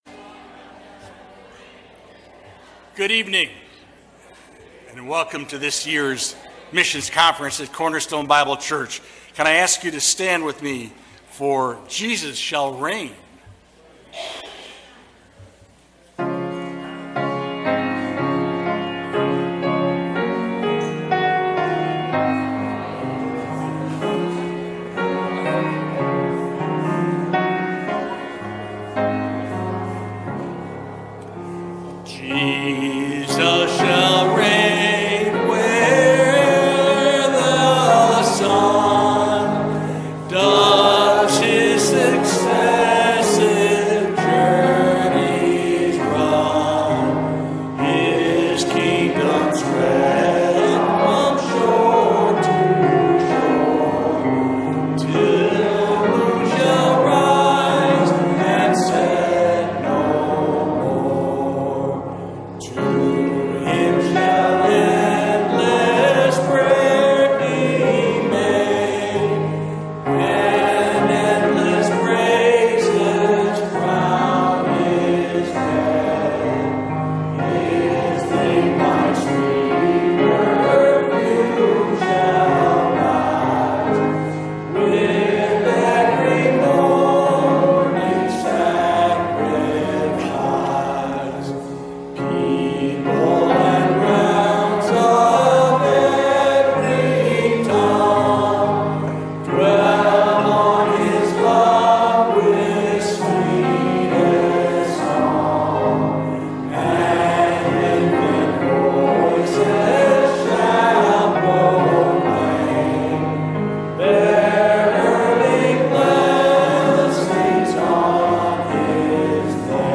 Sermon Detail
2023 Missions Conference - Friday Evening